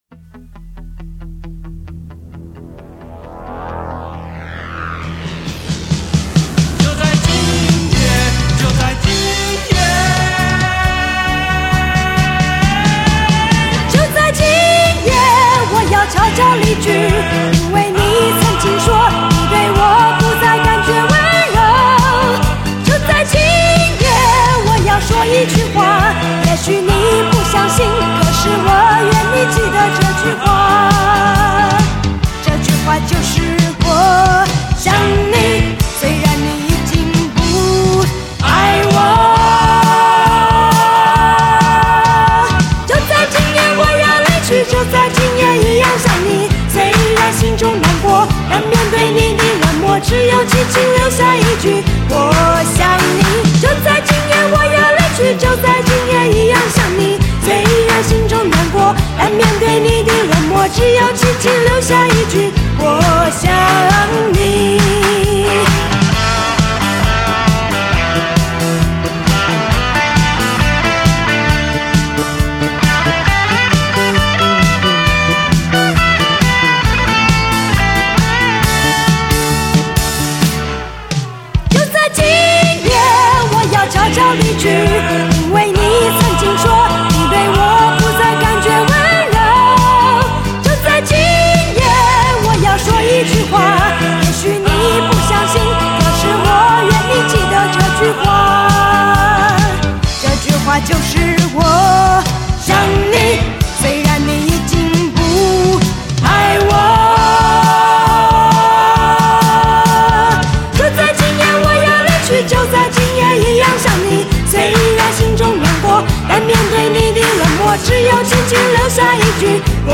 以连续12小节不间断的呐喊唱腔而眩惑你我耳目的